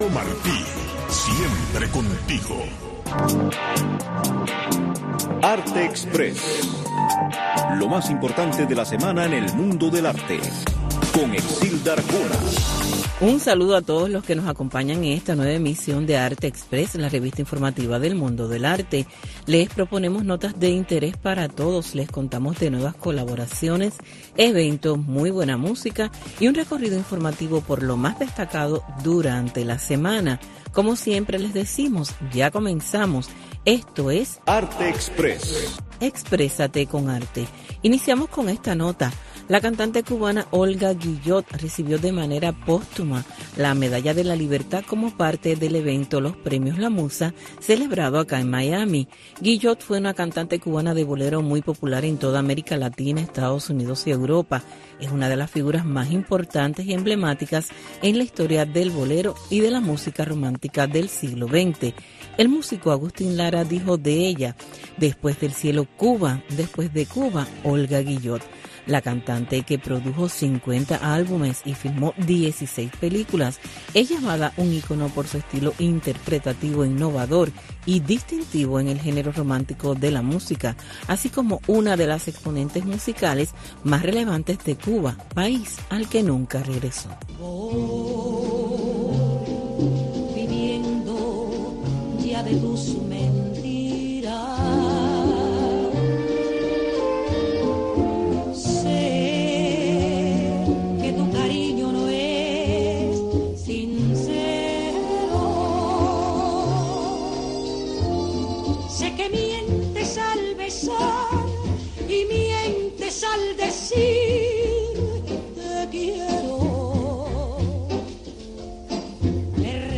Revista informativa con noticias, eventos, blogs cubanos, efemérides, música y un resumen de lo más importante de la semana en el mundo del arte.